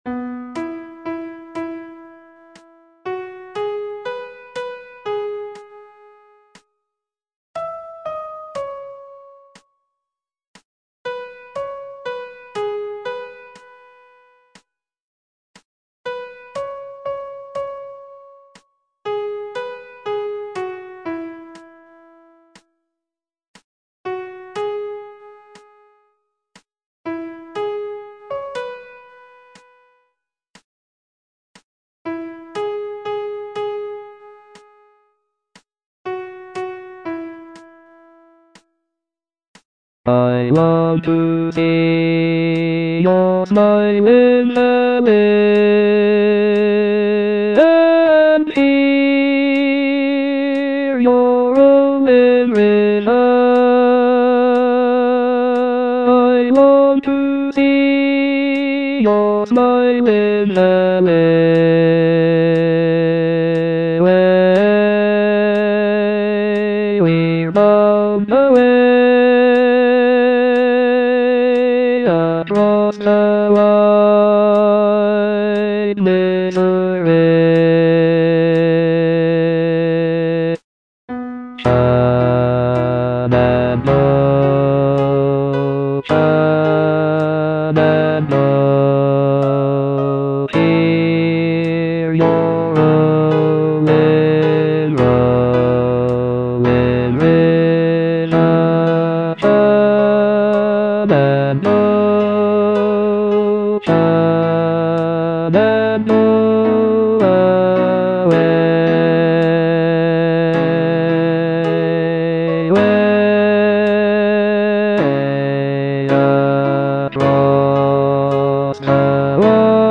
Bass I (Voice with metronome)